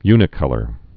(ynĭ-kŭlər)